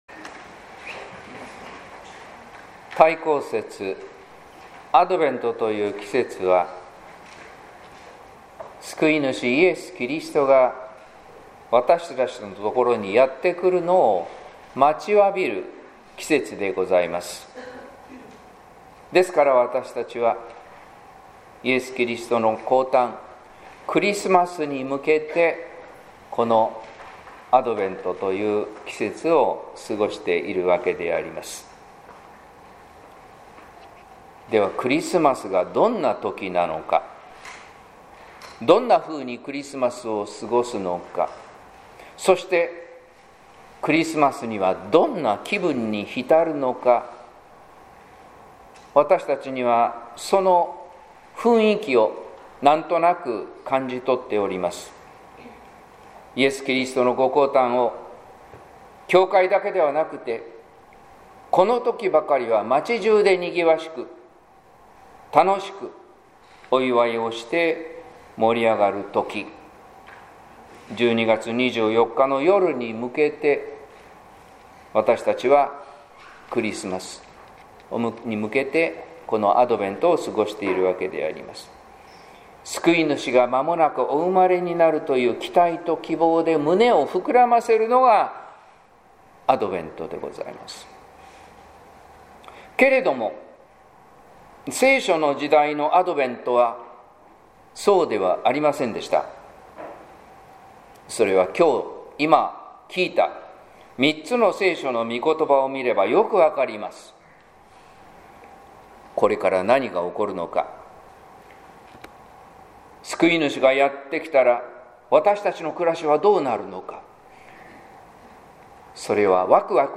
説教「それぞれのアドベント」（音声版） | 日本福音ルーテル市ヶ谷教会
説教「それぞれのアドベント」（音声版）